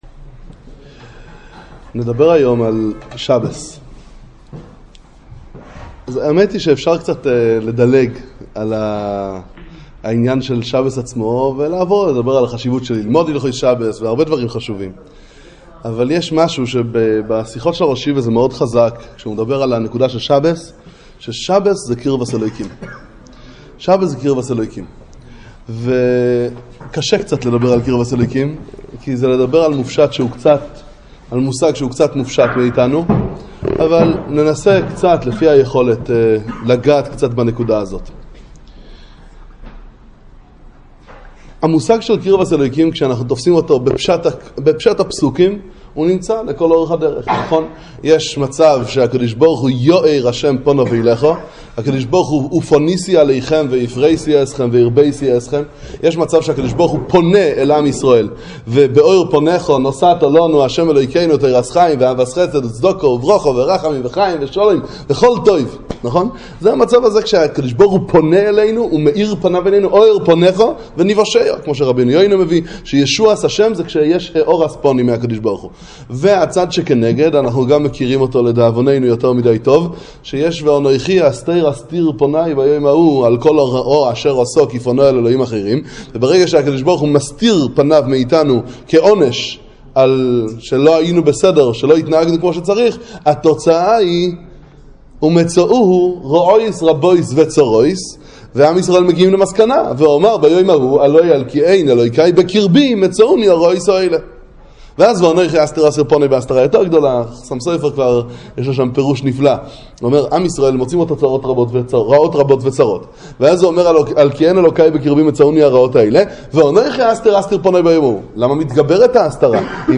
שיעור תורה